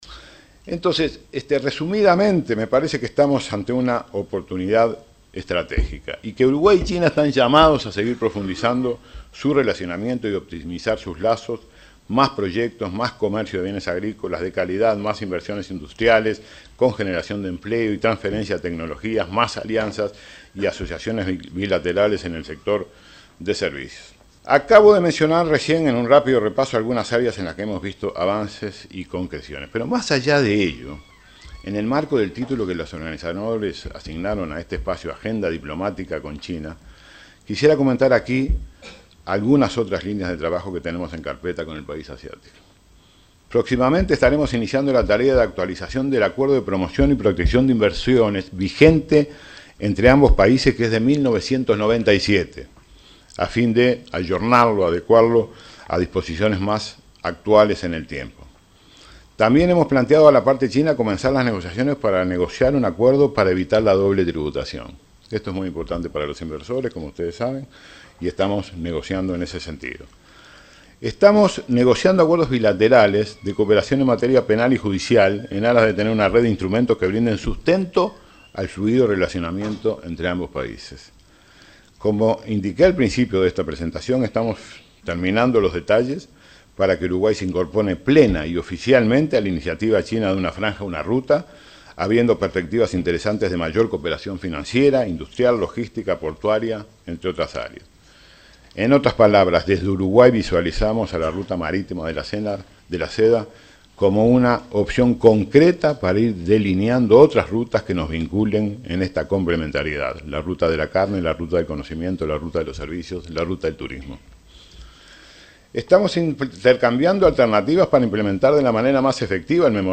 “Estamos ante una oportunidad estratégica y Uruguay y China están llamados a seguir profundizando su relacionamiento”, afirmó el canciller Nin Novoa, en el seminario “China, la oportunidad del siglo XXI”. Destacó que hay una buena perspectiva para colocar lácteos, vinos, aceite, cítricos, arándanos y miel uruguayos en China, con lo cual se propone diversificar la exportación de lana, cuero, pesca, soja, celulosa y carne.